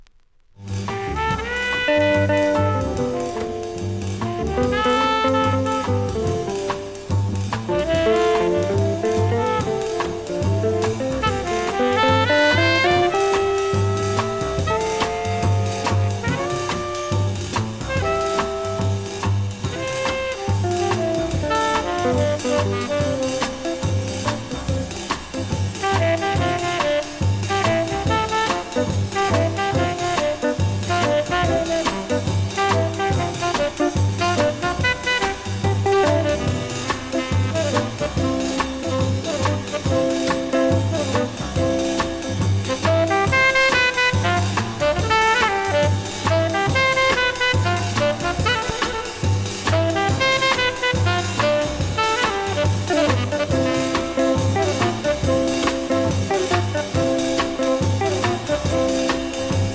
INSTRUMENTAL
プレス起因か45秒過ぎに1発だけノイズありますので試聴で確認下さい。